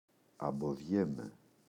αμποδιέμαι [aboꞋðʝeme]